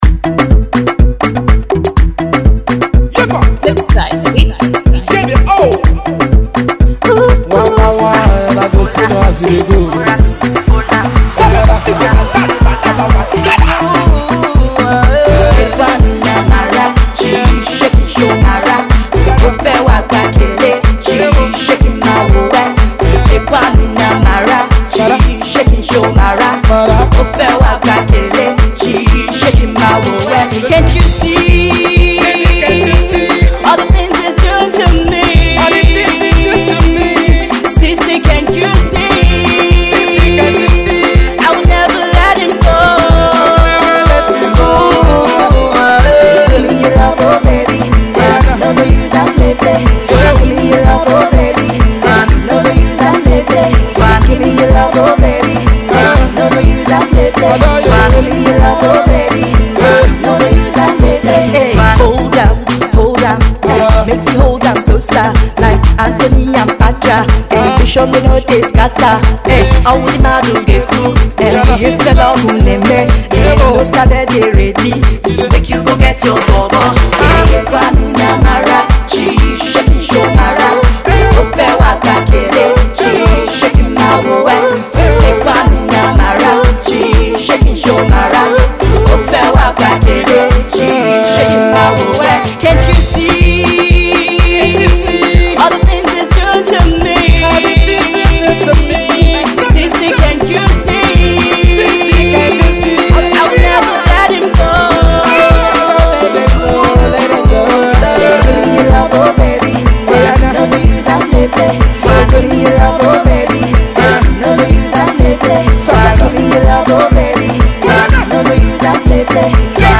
AudioPop
club banger